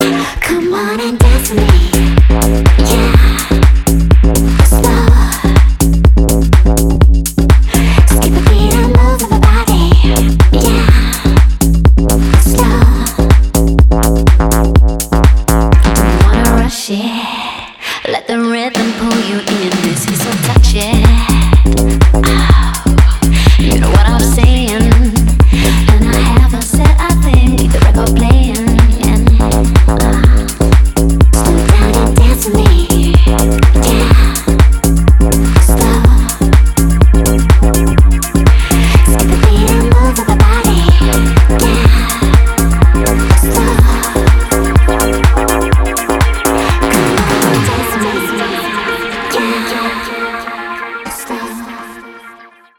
• Качество: 320, Stereo
чувственные
Tech House
house
стильные
клубная музыка
танцевальная музыка
Club Dance